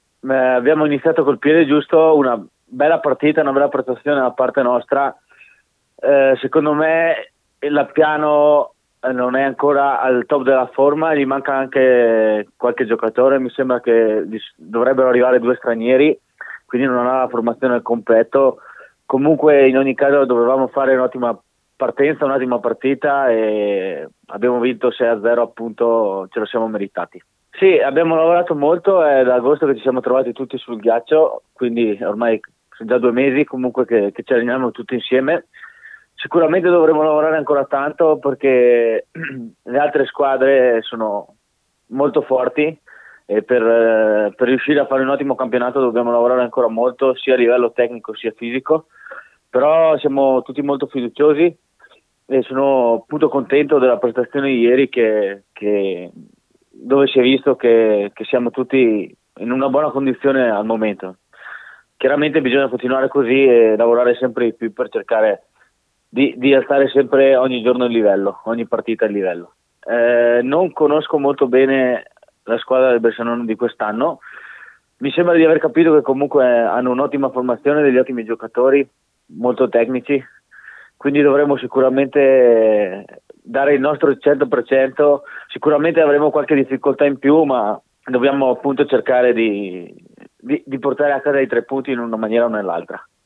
AI MICROFONI DI RADIO PIÙ